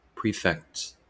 Ääntäminen
Ääntäminen US Haettu sana löytyi näillä lähdekielillä: englanti Käännöksiä ei löytynyt valitulle kohdekielelle. Prefects on sanan prefect monikko.